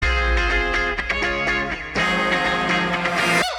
гитара
короткие